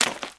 STEPS Swamp, Walk 29.wav